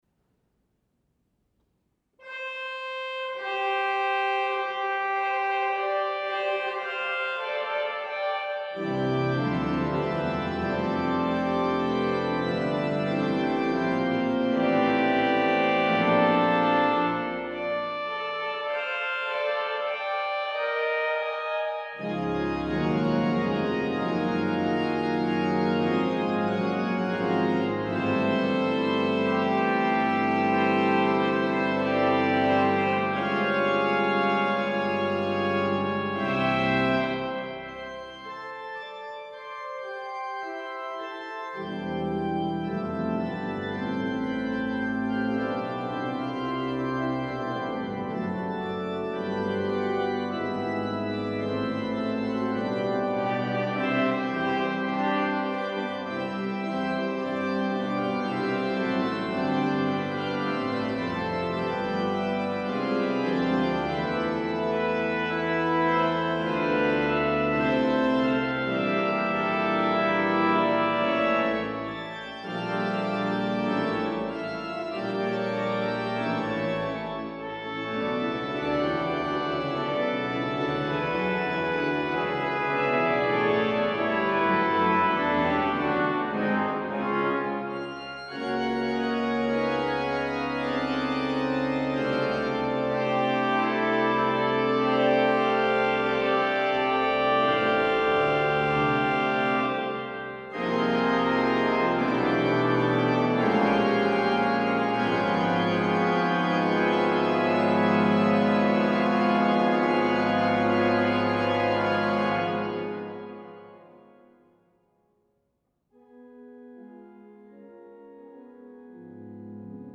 • Music Type: Organ
• harmonically interesting with changing key centers